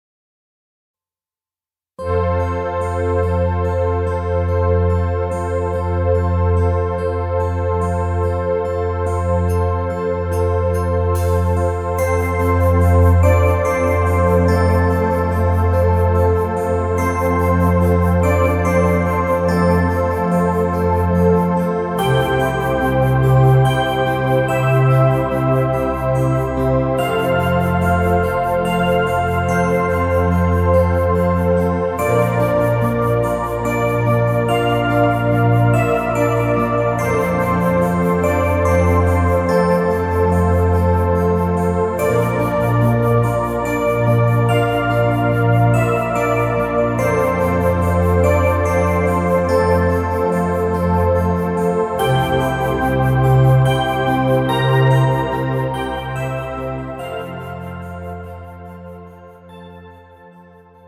음정 F 키
장르 가요 구분 Pro MR